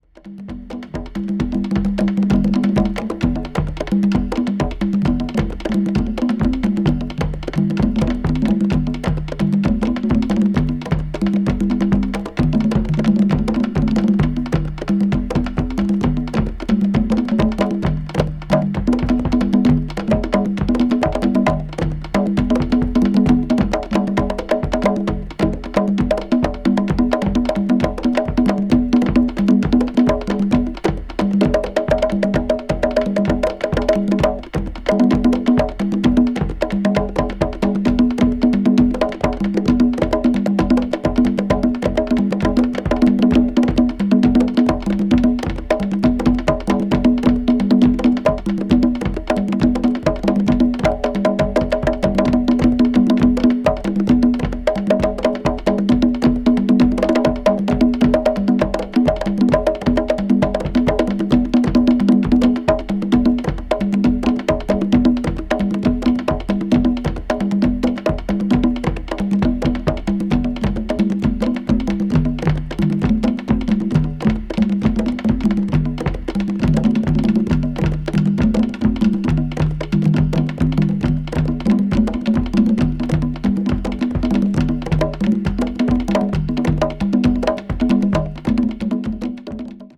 media : EX/EX(わずかにチリノイズが入る箇所あり)
africa   ethnic   percussion   primitive   world music